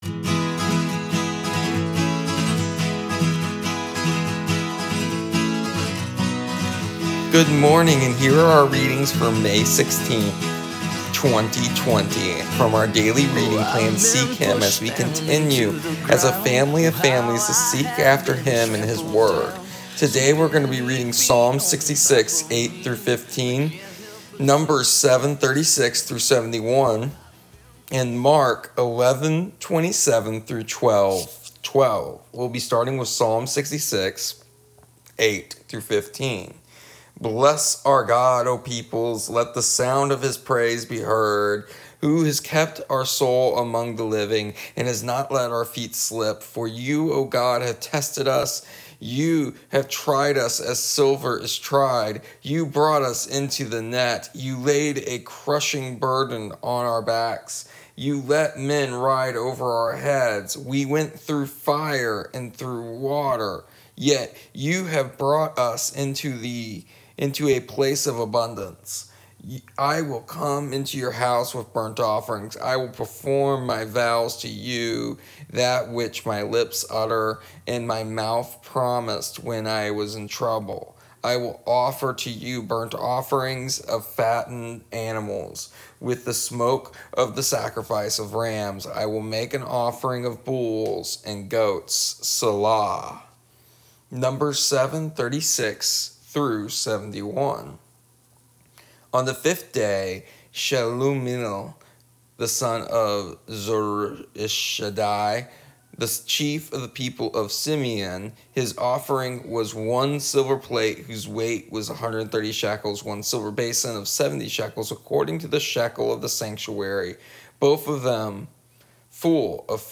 Here is our daily readings from our daily reading plan in an audio format.